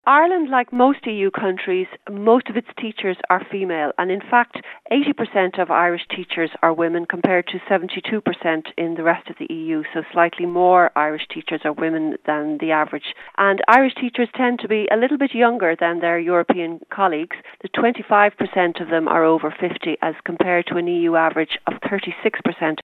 EC Radio Ireland | Report 1215